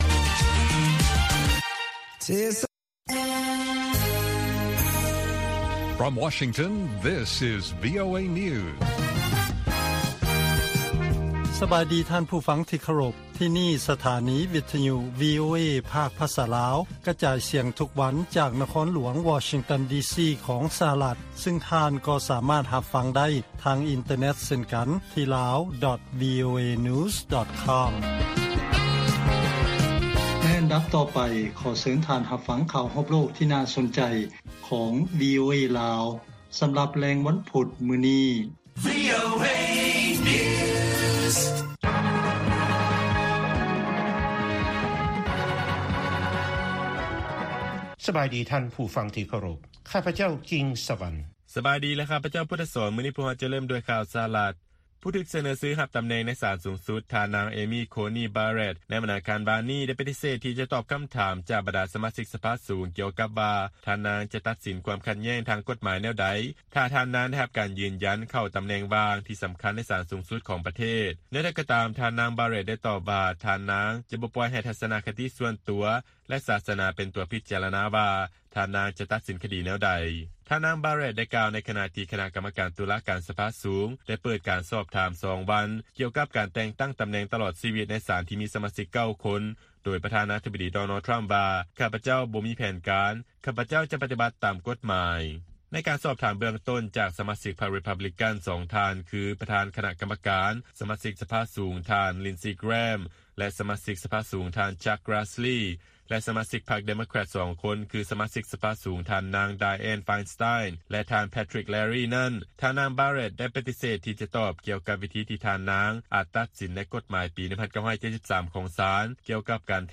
ວີໂອເອພາກພາສາລາວ ກະຈາຍສຽງທຸກໆວັນ. ຫົວຂໍ້ຂ່າວສໍາຄັນໃນມື້ນີ້ມີ: 1) ທາງການລາວ ຈະອະນຸຍາດໃຫ້ຖ້ຽວບິນເຊົ່າເໝົາລຳ ຈາກປະເທດທີ່ບໍ່ມີການລະບາດຂອງເຊື້ອໄວຣັສ ໂຄວິດ-19 ເຂົ້າມາໃນປະເທດໄດ້.